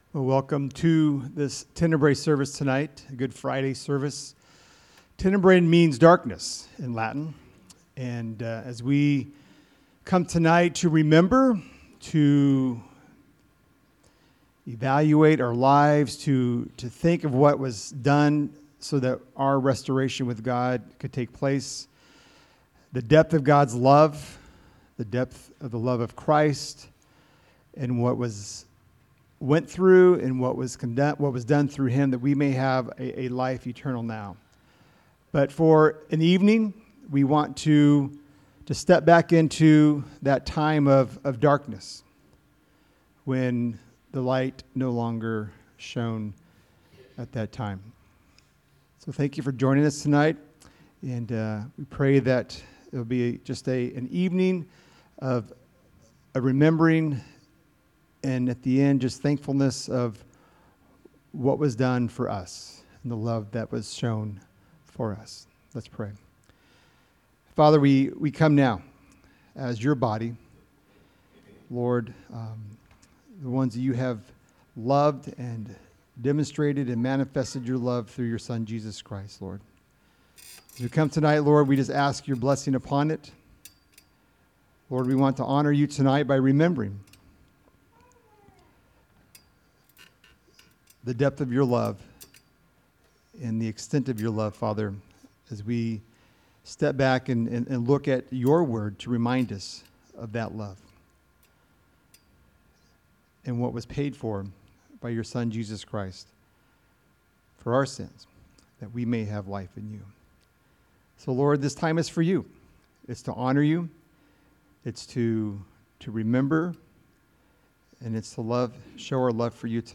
Join us as our elders lead us in remembrance of our Savior's sacrifice and contemplation of our sins which made it necessary.